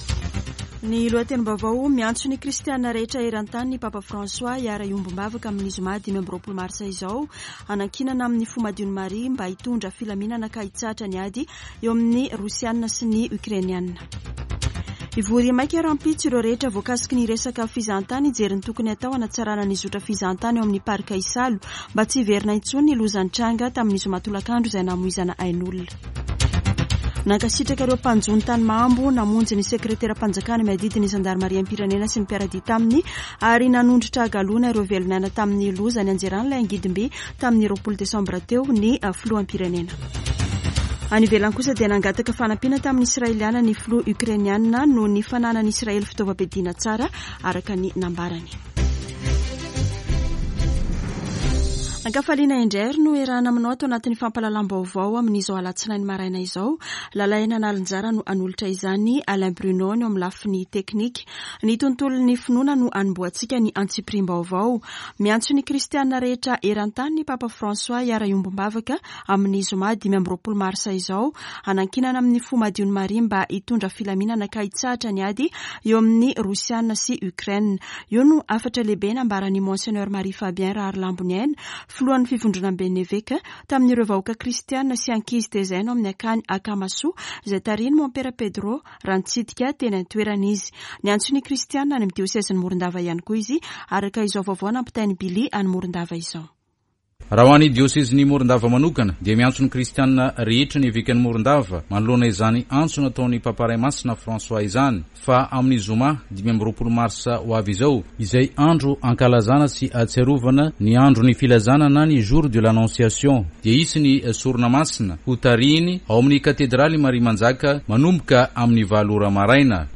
[Vaovao maraina] Alatsinainy 21 marsa 2022